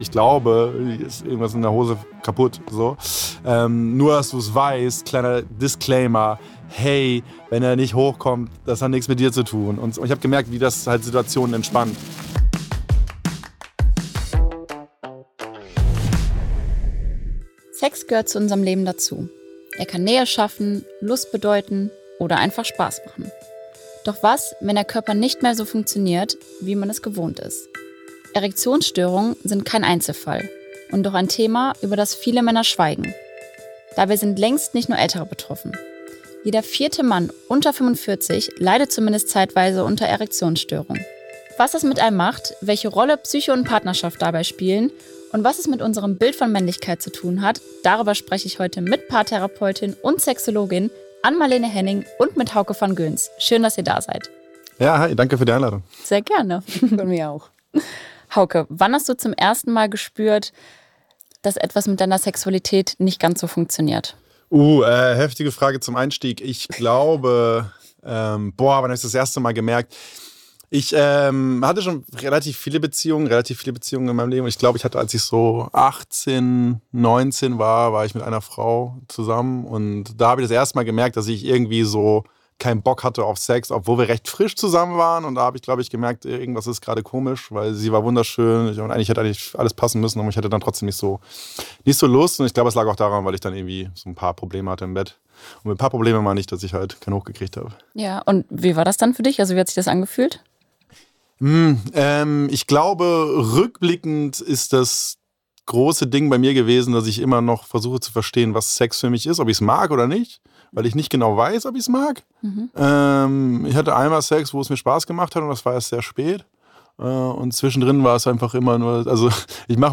Gemeinsam mit Paar- und Sexualtherapeutin Ann-Marlene Henning sprechen wir darüber, wie sehr Männlichkeitsbilder und Leistungsdruck unsere Sexualität prägen, welche Ursachen für Erektionsstörungen es gibt, warum die Psyche eine so große Rolle spielt und wie Paare Intimität jenseits von Penetration neu entdecken können.